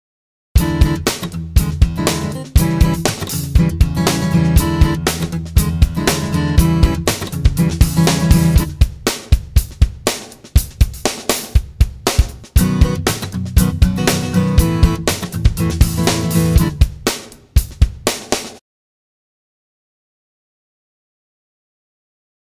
Apprendre à programmer des strummings de guitare sans utiliser de loop.